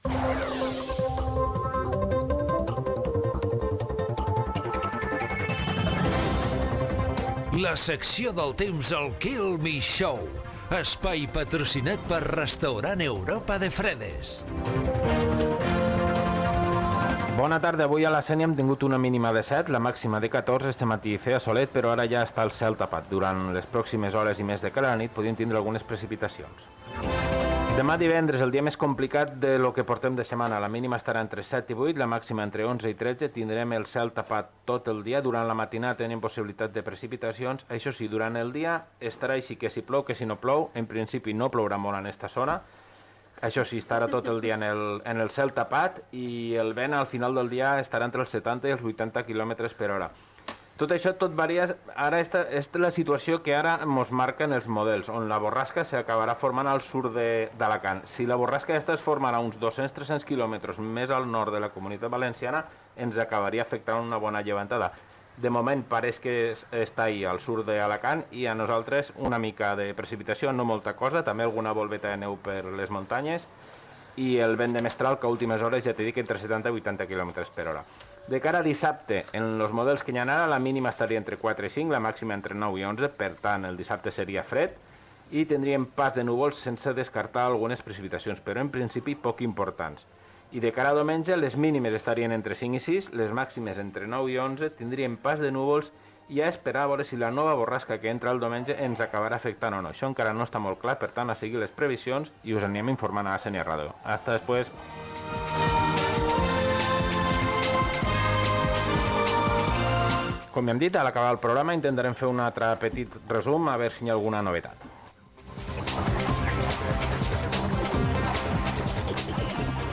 Indicatiu del programa, estat del temps, entrevista a les regidores Alma Gimeno i Laia Sanz sobre la festa del carnestoltes.
Informatiu